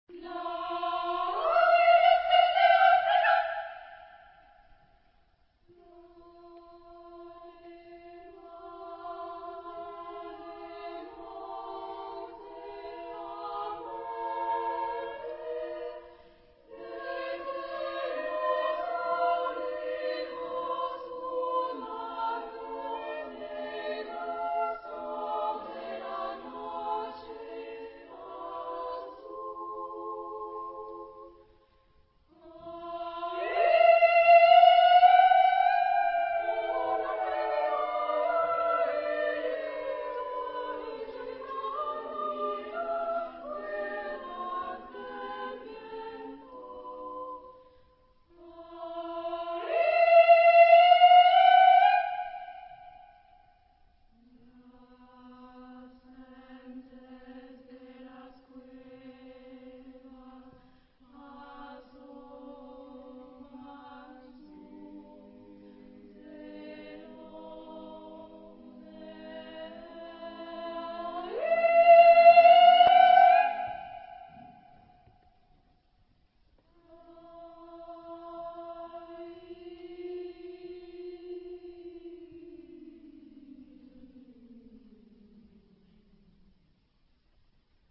Tonalité : mi (centré autour de)